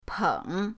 peng3.mp3